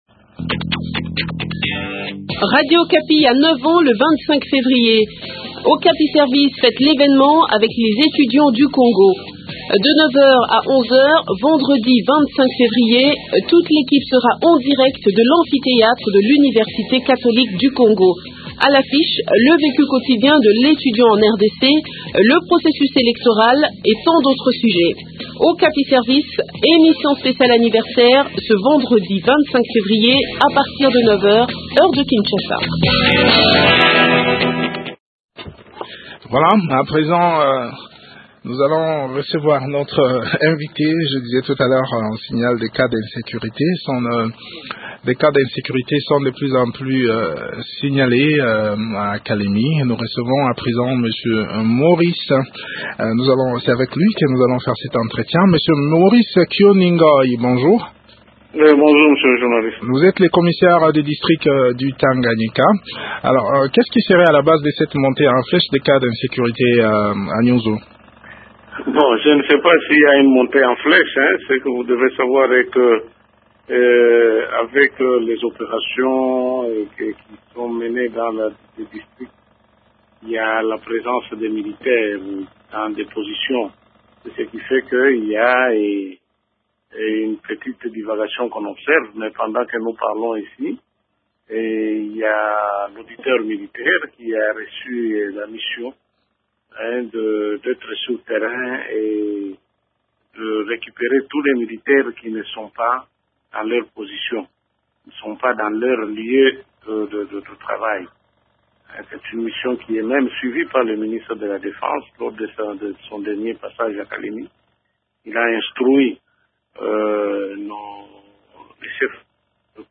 Maurice Kyoni Ngoie, commissaire de district du Tanganyika livre quelques éléments de réponse dans cet entretien